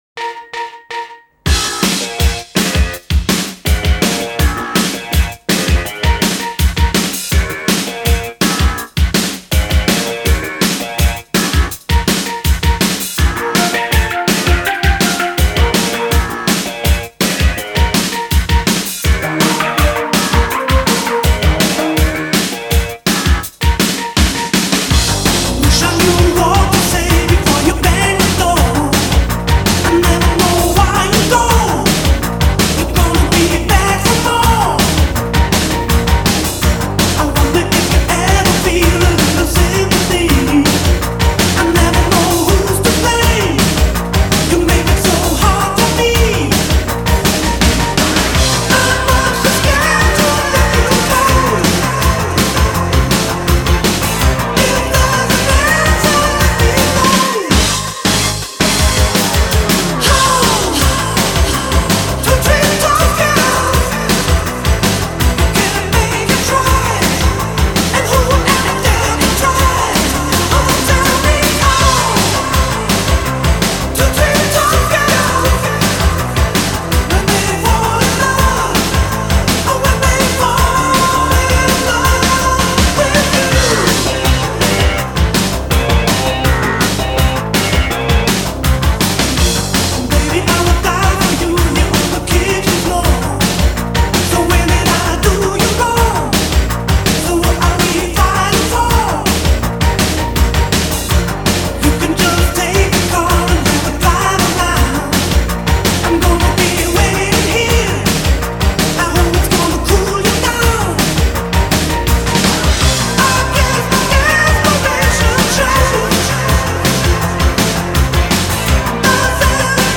Жанр: Pop/Synth-pop